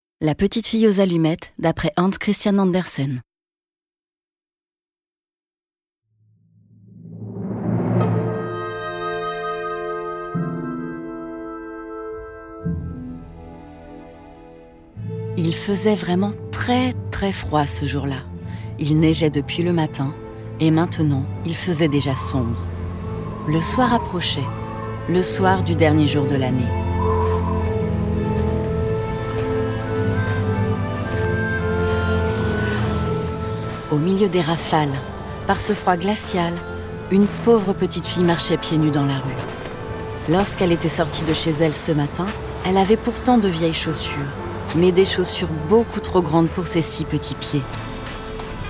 Le conte majestueux du grand auteur Hans Christian ANDERSEN illustré dans l'univers sonore contemporain de nos grandes villes, riche de sa réflexion universelle sur l'indifférence face à la pauvreté.